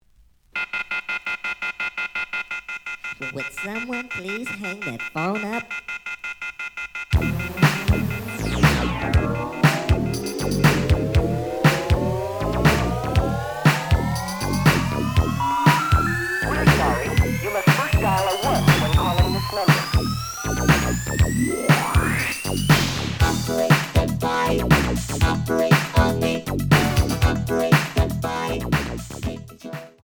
試聴は実際のレコードから録音しています。
●Genre: Disco
●Record Grading: EX- (盤に若干の歪み。多少の傷はあるが、おおむね良好。プロモ盤。)